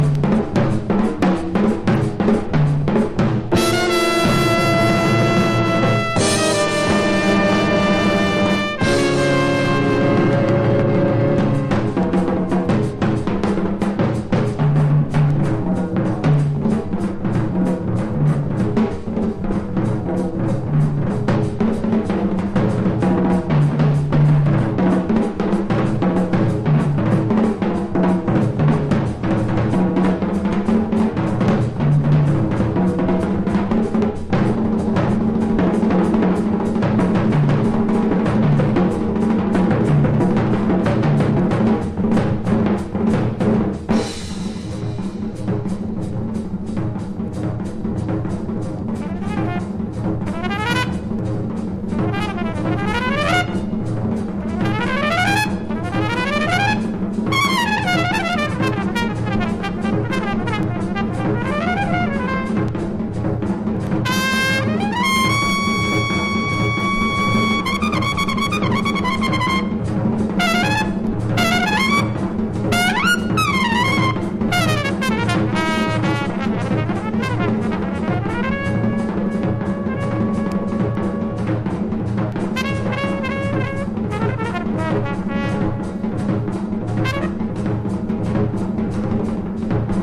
圧巻のドラムソロ満載です。
# BIGBAND / SWING# ハードバップ